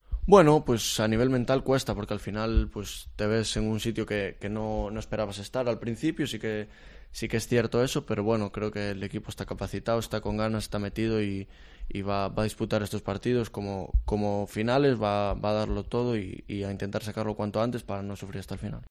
Brais Méndez ha concedido esta mañana una extensa entrevista a la Cadena COPE en la que ha analizado toda la actualidad del equipo vigués. El joven jugador de Mos ha reconocido que el equipo está pasando un mal momento a nivel mental, porque no esperaban verse tan abajo en la clasificación.